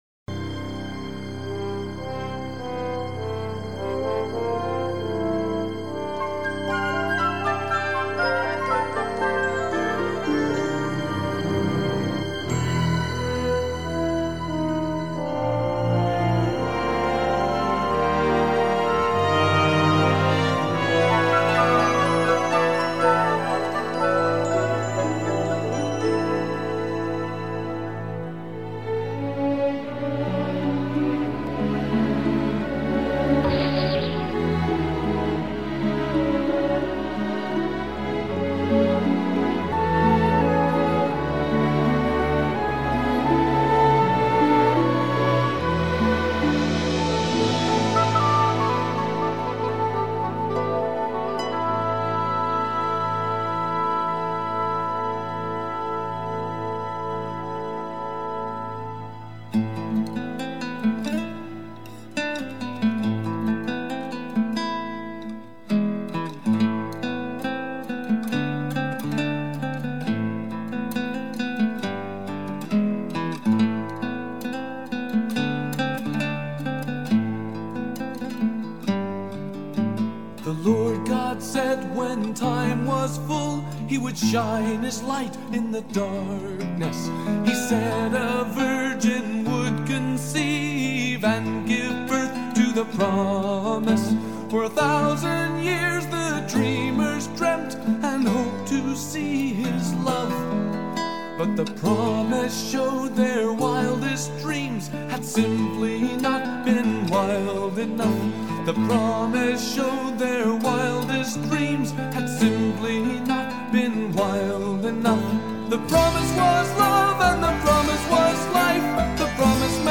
Audio: RCR4JC-AM broadcasting songs of Christmas hope!